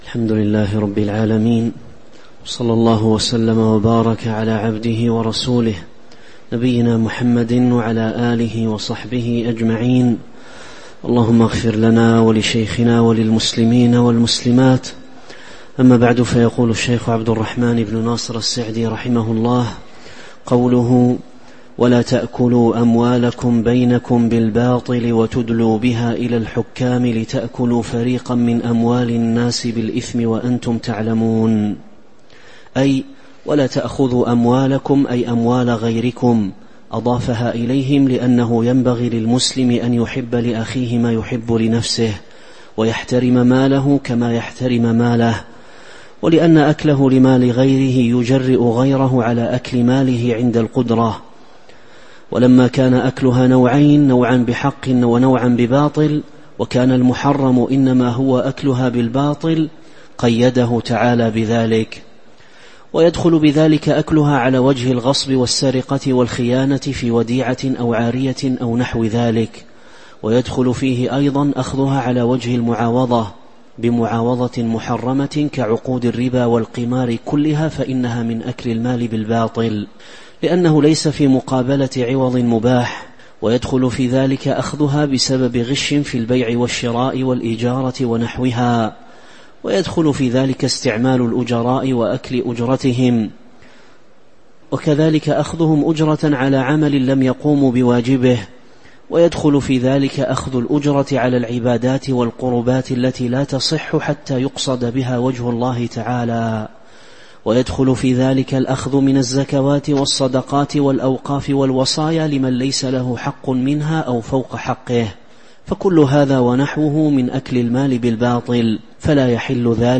تاريخ النشر ١٧ رجب ١٤٤٦ هـ المكان: المسجد النبوي الشيخ